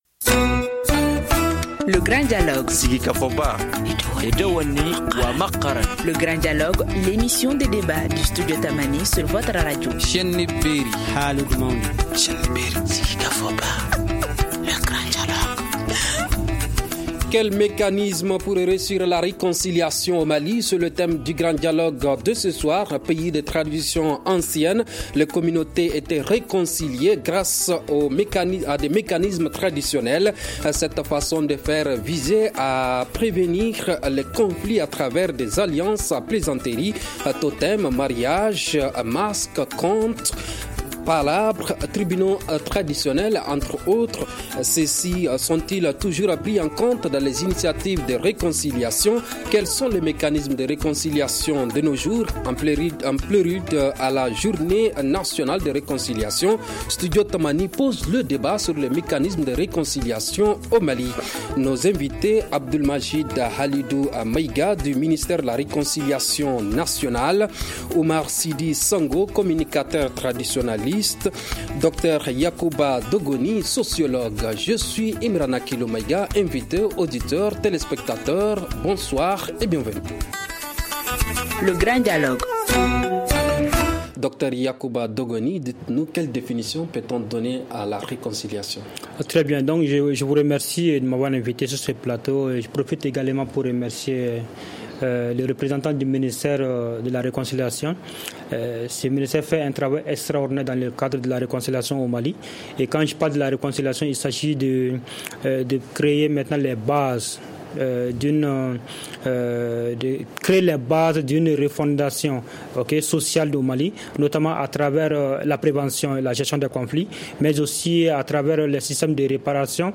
Ceux-ci sont-ils toujours pris en compte dans les initiatives de réconciliation? Quels sont les mécanismes de réconciliation de nos jours ? En prélude à la semaine nationale de réconciliation, Studio Tamani pose le débat sur les mécanismes de réconciliation dans le pays.